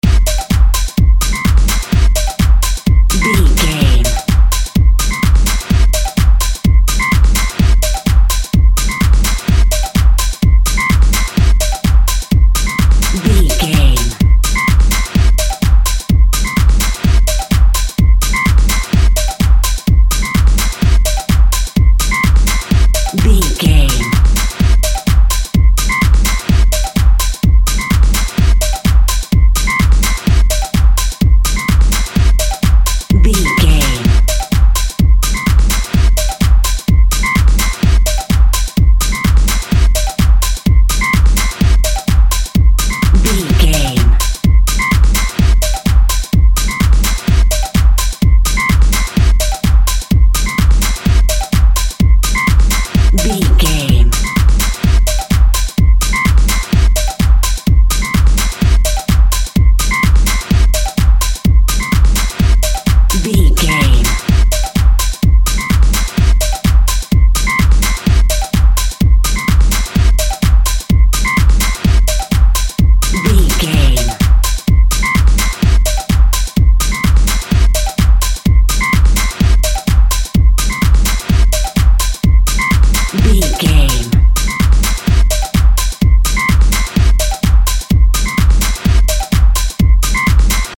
Atonal
uplifting
futuristic
hypnotic
dreamy
tranquil
smooth
drum machine
synthesiser
house
techno
electro
synth lead
synth bass